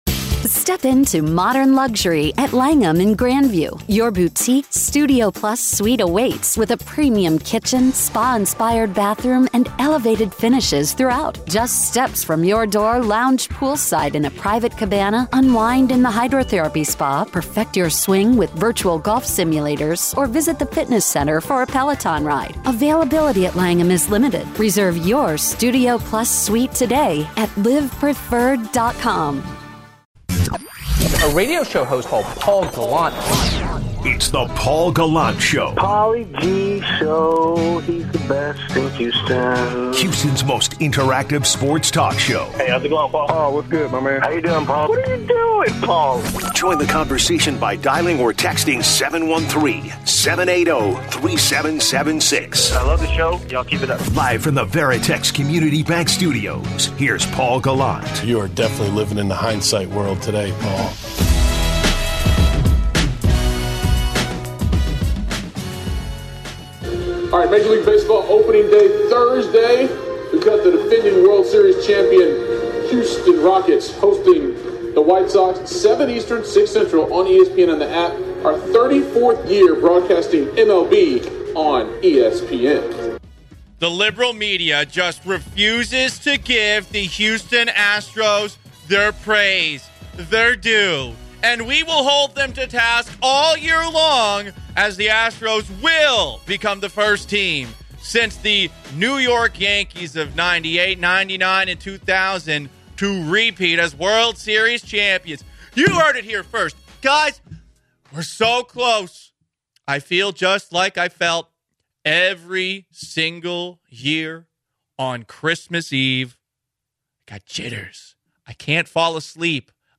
they also have a special guest Doug Glanville talking about the Astros and the MLB rule changes for this season.